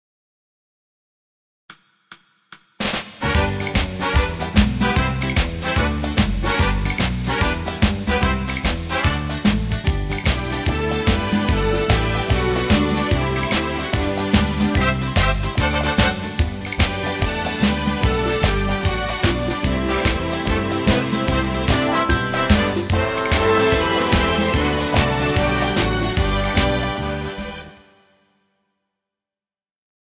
INSTRUMENTAL
Pop